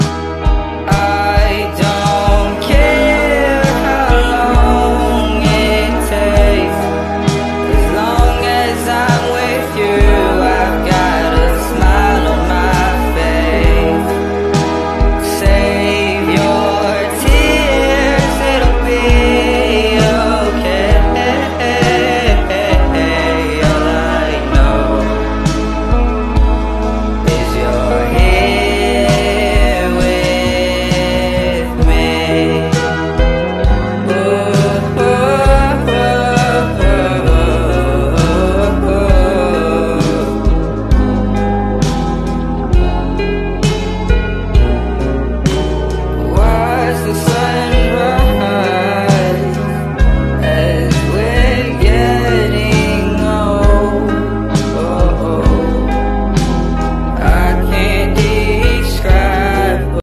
Part 110| A rare behind-the-scenes gem caught on a fan’s phone 🎥✨ During one of the early Running Man missions where the members and guests had to test their jumping skills, things took a sudden turn.